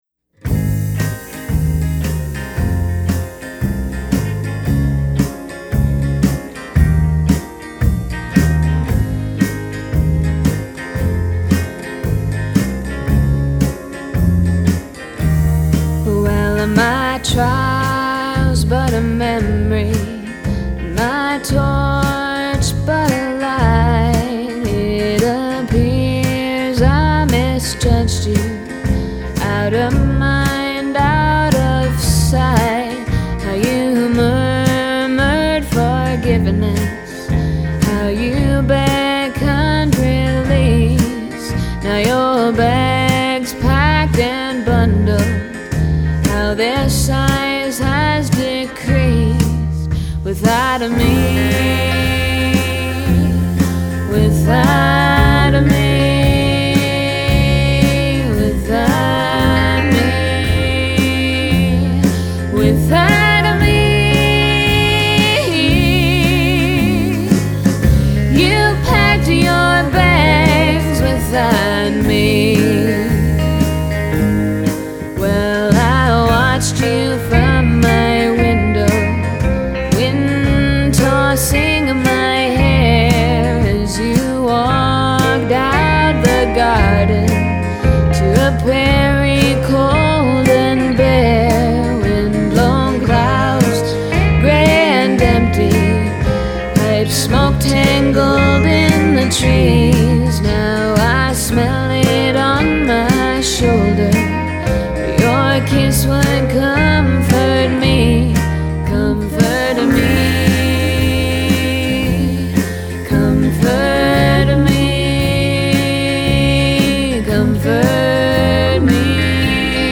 Recorded at The Lincoln County Social Club.
Vocals, acoustic Guitar
drums, tambourine, shaky skull.